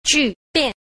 3. 聚變 – jùbiàn – tụ biến (tổng hợp)